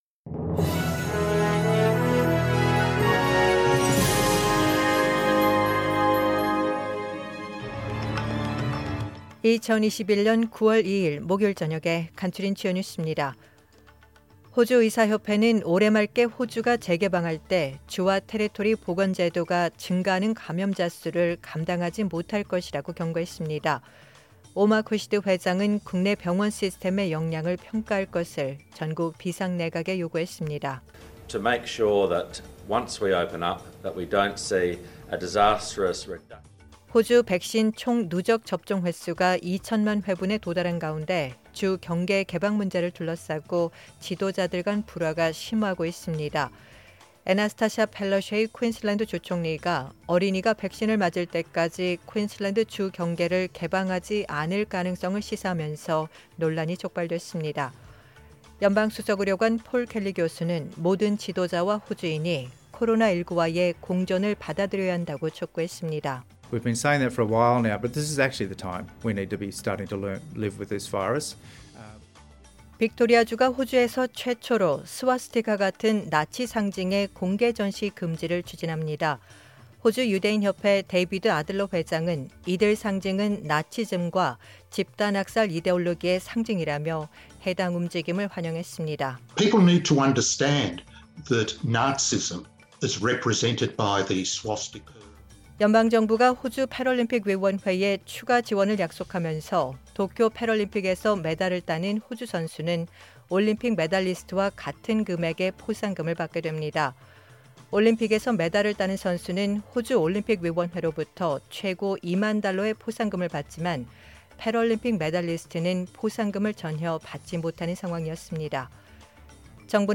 2021년 9월 2일 목요일 저녁의 SBS 뉴스 아우트라인입니다.